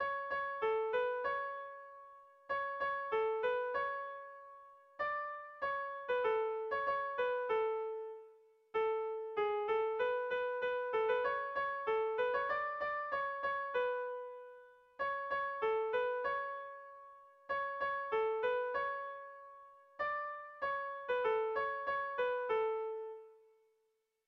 Seiko handia (hg) / Hiru puntuko handia (ip)
ABA